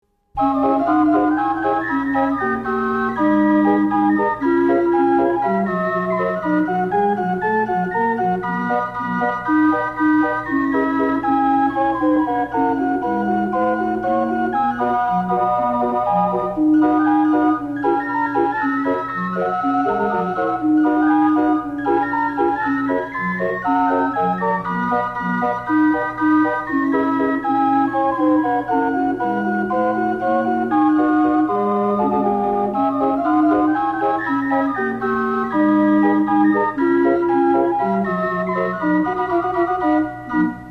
AUTHENTIC CIRCUS CALLIOPE MUSIC